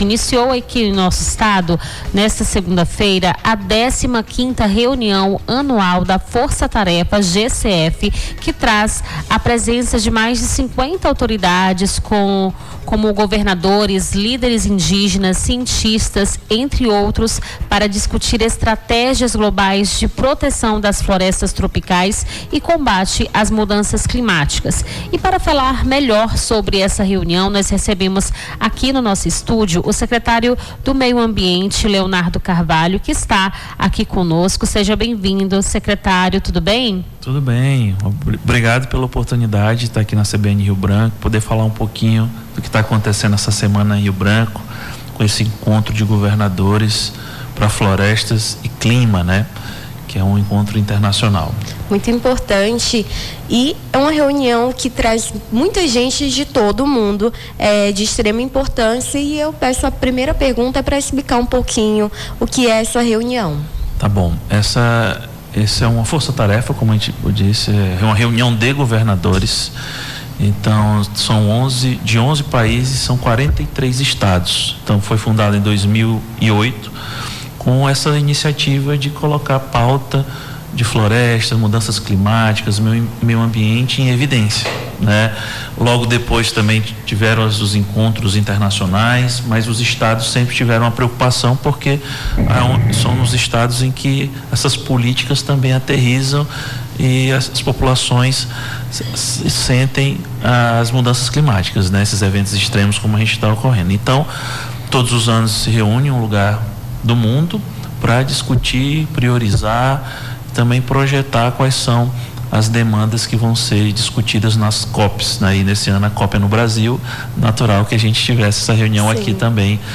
Nome do Artista - CENSURA - ENTREVISTA 15a REUNIÃO ANUAL DA FORÇA TAREFA (20-05-25).mp3